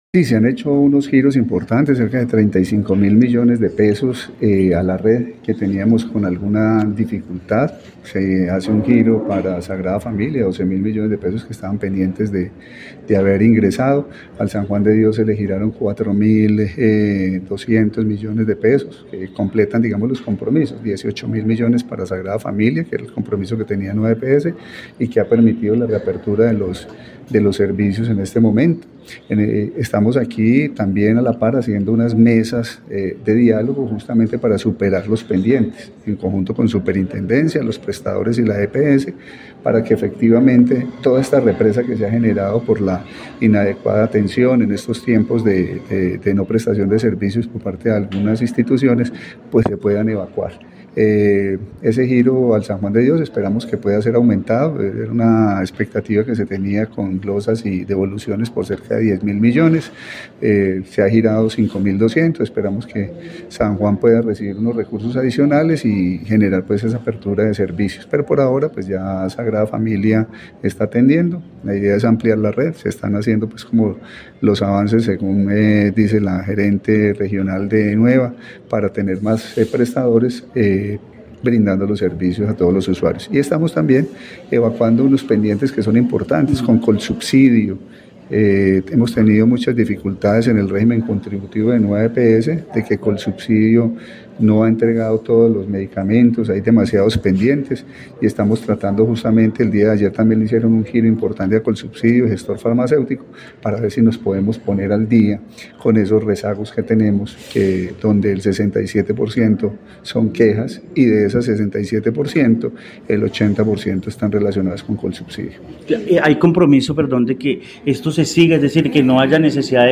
Carlos Alberto Gómez, secretario de salud y las EPS
En el noticiero del mediodía de Caracol Radio Armenia, el secretario de salud del departamento del Quindío, Carlos Alberto Gómez Chacón confirmó la buena noticia para los más de 240.000 usuarios de la Nueva EPS en los 12 municipios y es que la clínica La Sagrada Familia nuevamente atenderá a los usuarios de esta EPS.